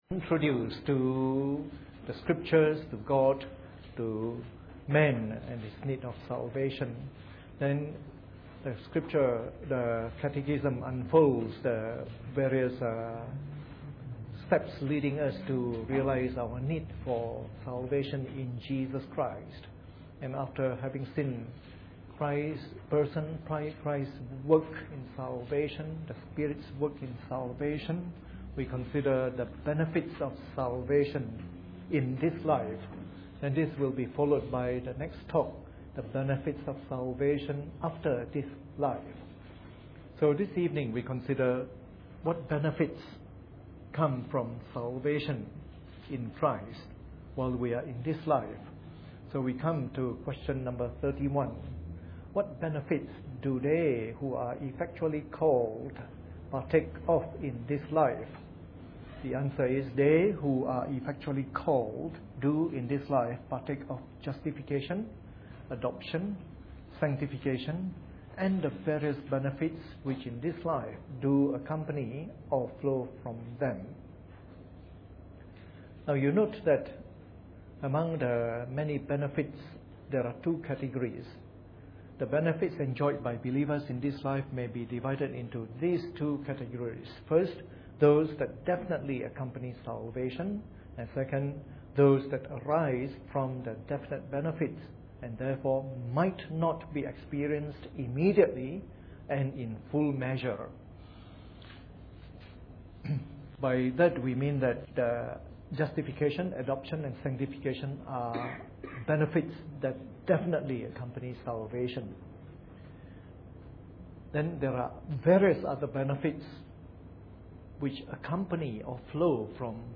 Preached on the 30th of March 2011 during the Bible Study from our current series on the Shorter Catechism.